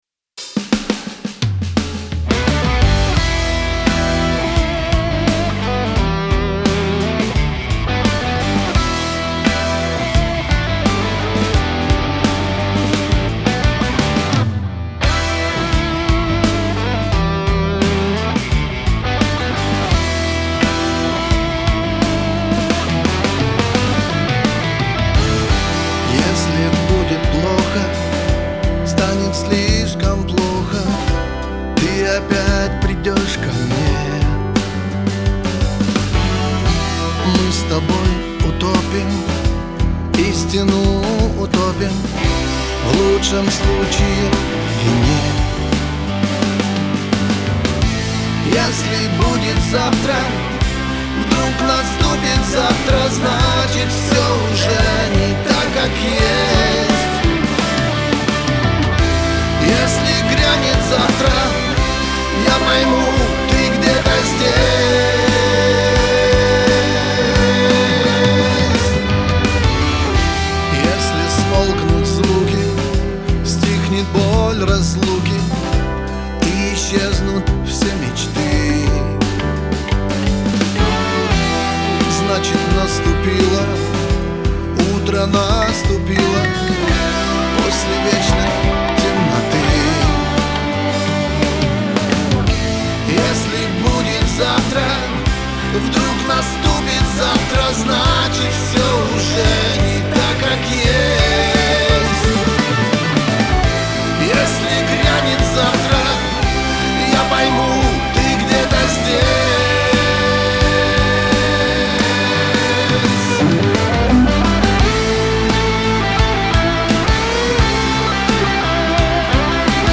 клавишные
программирование барабанов
гитары (акустика, электро, бас), бэк-вокал